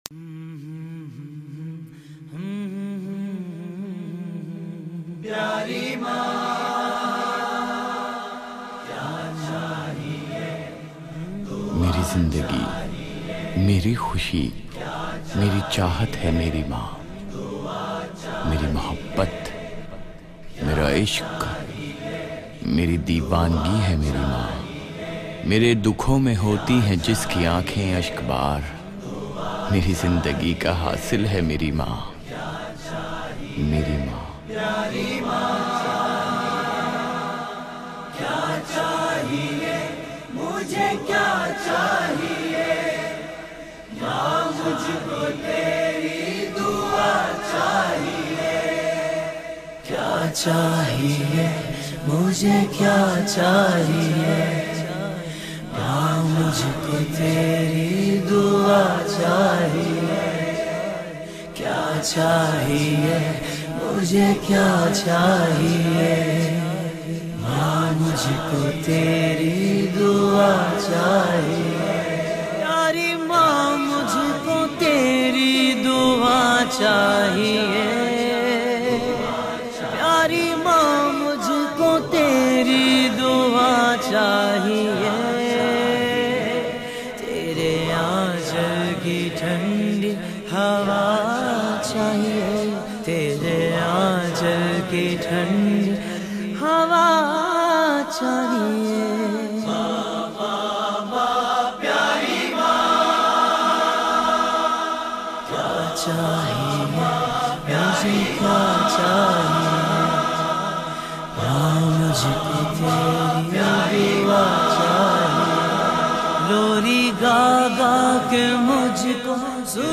soulful and heartwarming naat recitations.